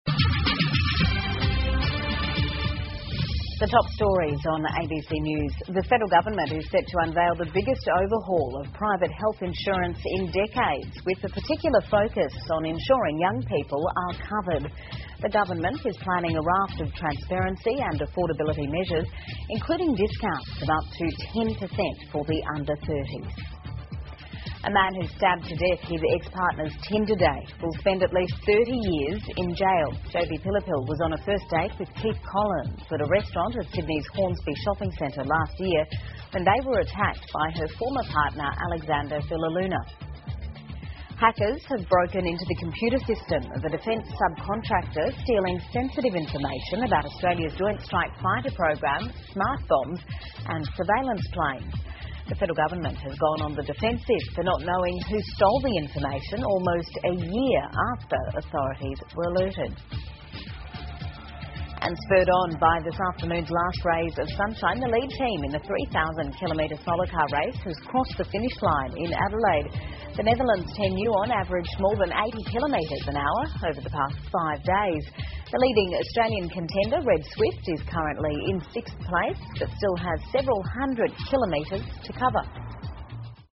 澳洲新闻 (ABC新闻快递) 澳拟推医疗保险改革 国防部承包商遭黑客入侵 听力文件下载—在线英语听力室